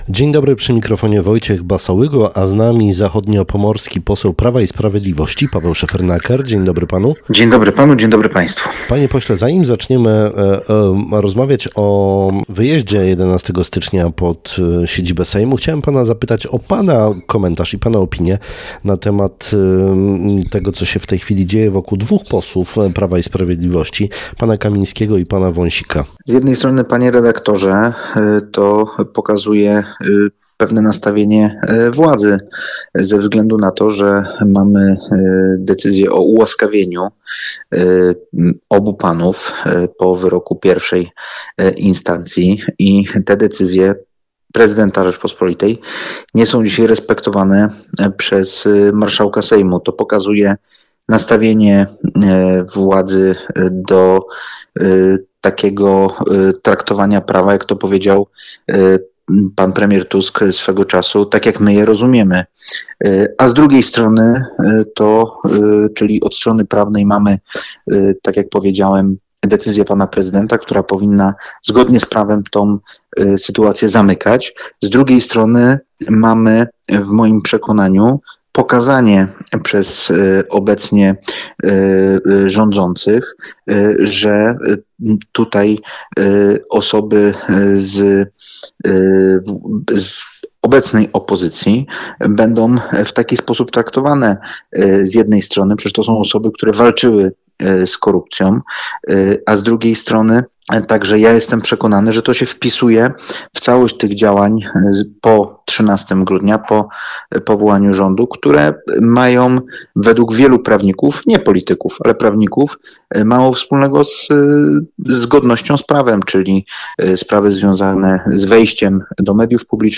Po zimowych feriach Prawo i Sprawiedliwość przedstawi kandydatów na wójtów, burmistrzów, prezydentów i radnych zachodniopomorskich gmin. Mówił dziś o tym na antenie Twojego Radia poseł Paweł Szefernaker.